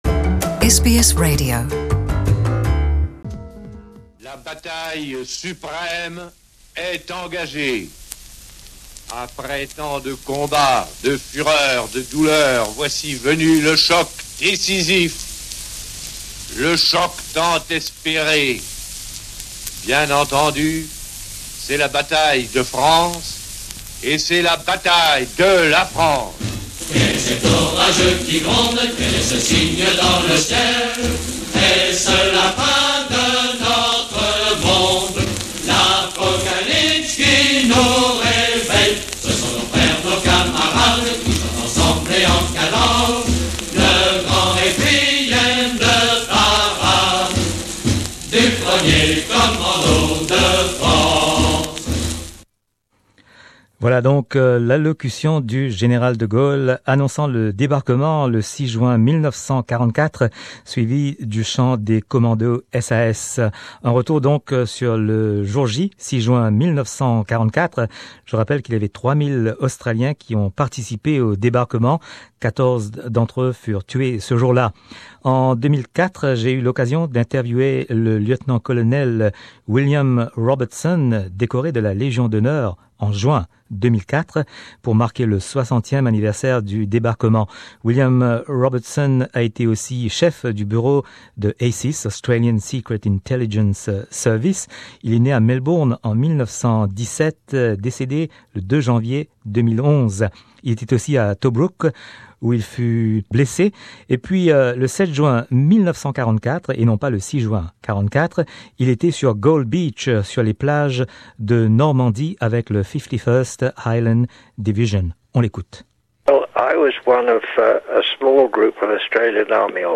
Pour marquer les commémorations du 75è anniversaire du débarquement en Normandie, un retour sur le Jour J du 6 juin 1944 avec des extraits de nos archives de SBS French. Des interviews